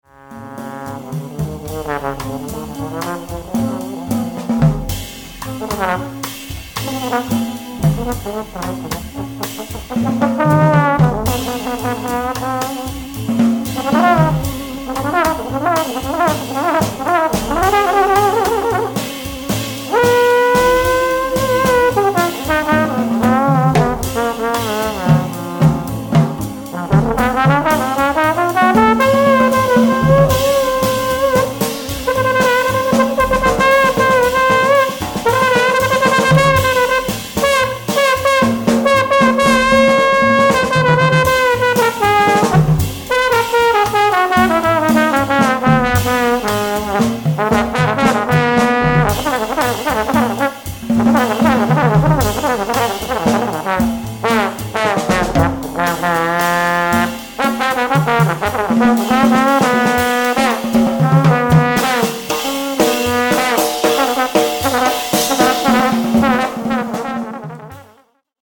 The Best In British Jazz
Recorded Doz Studios, Tunbridge Wells November 2008